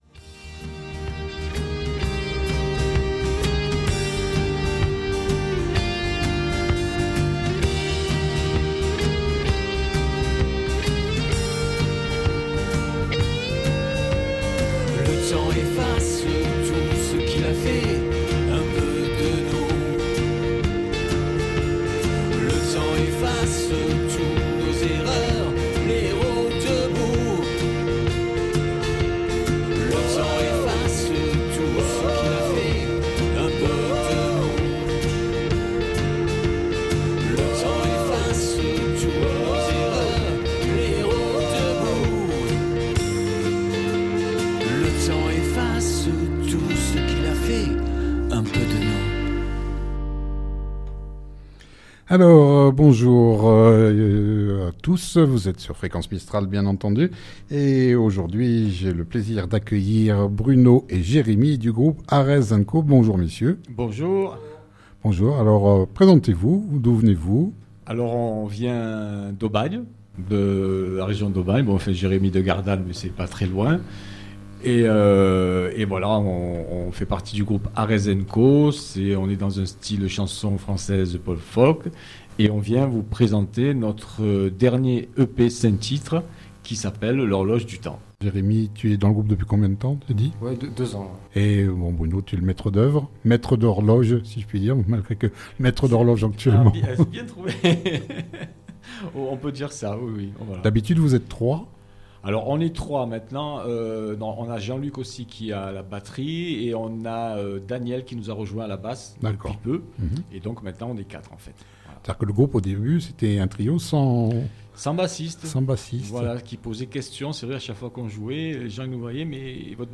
Ils sont venus jouer live quelques titres de leur répertoire et à la fin une petite surprise vous attend...